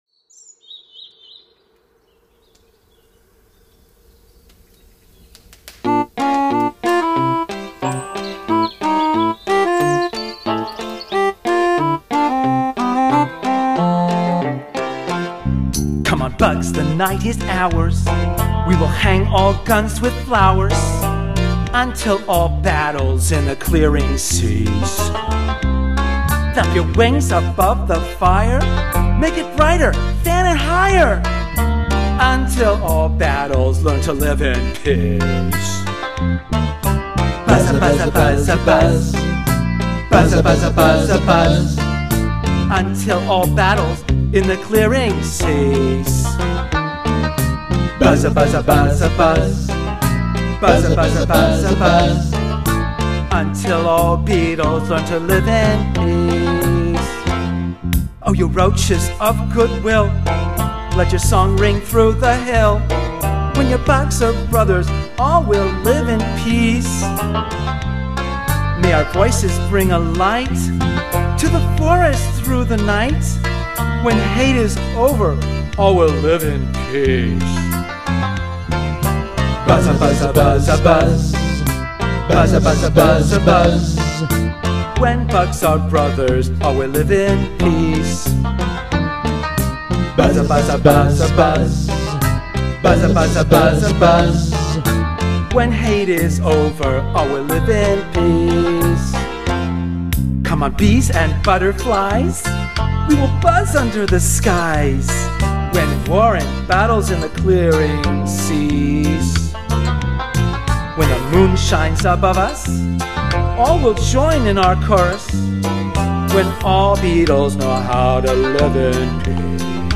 ici avec le monsieur qui chante (fichier mp3 replet)